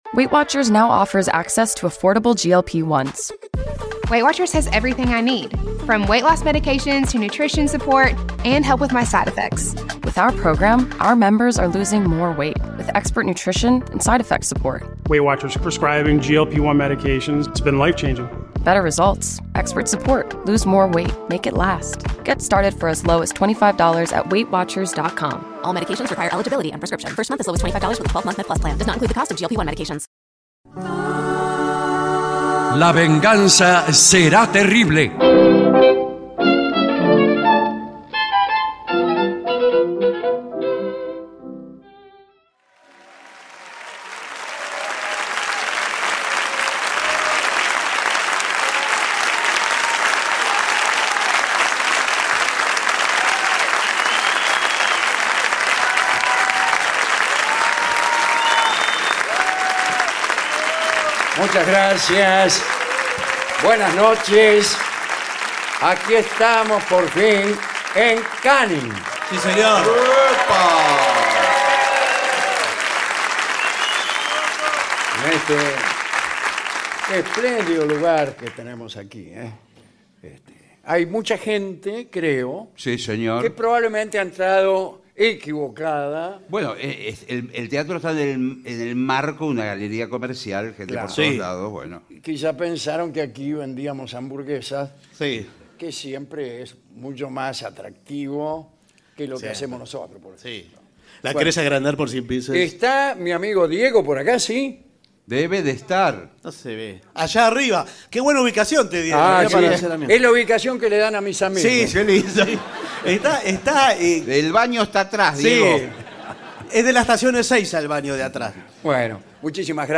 La Venganza Será Terrible en Canning - 2 de enero 2024 - LVST 2/1/24